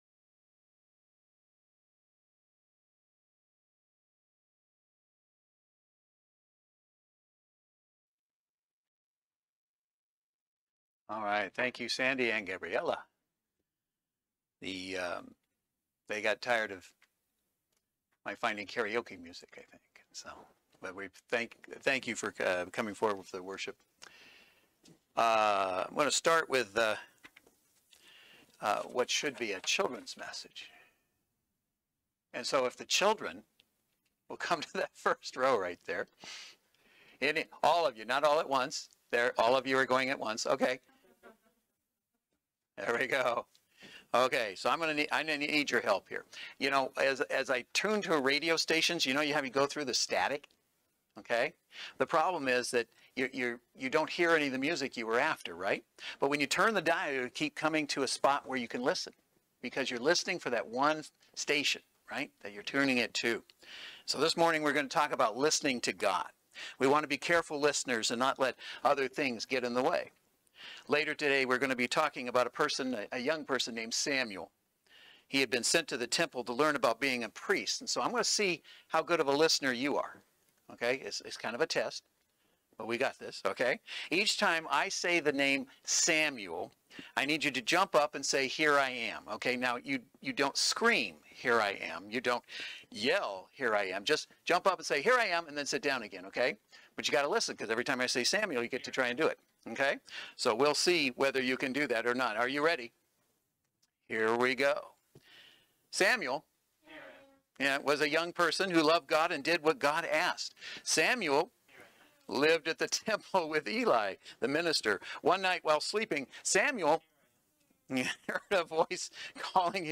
Service Type: Saturday Worship Service